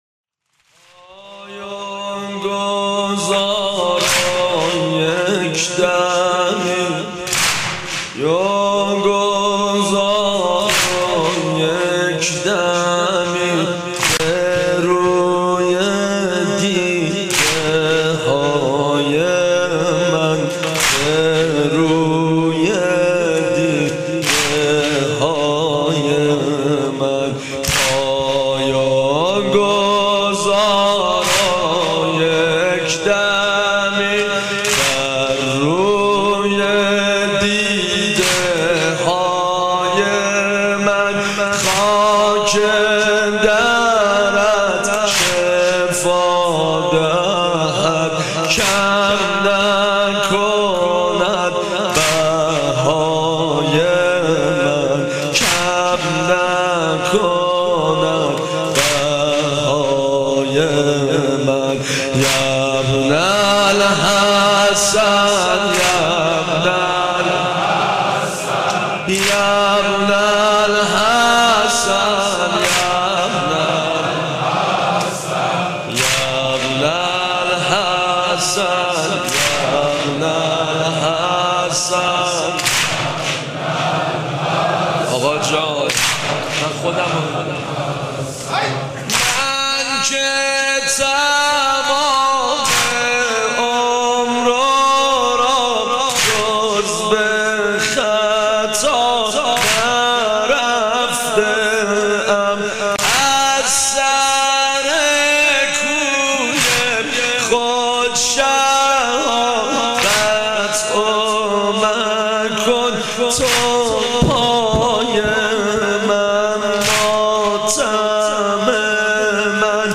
مداحی نوحه دیدگاه‌ها